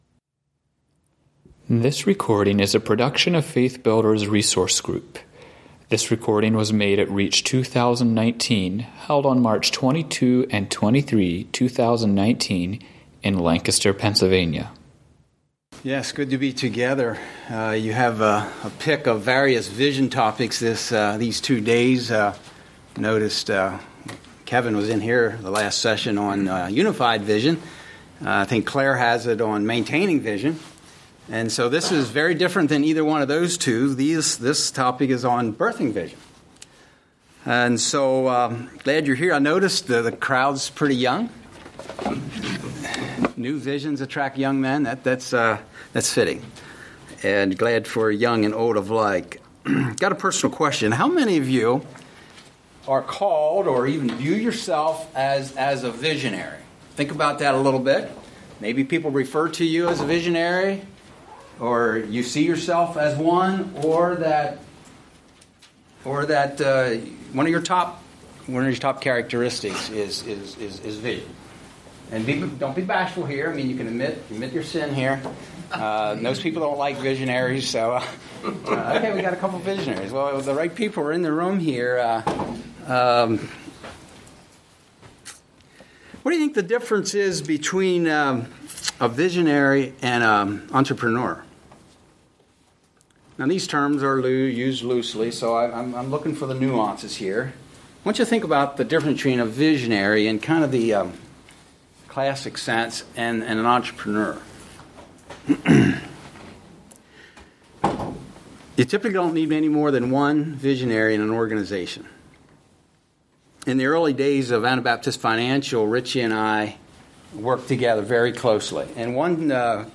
Home » Lectures » Birthing New Vision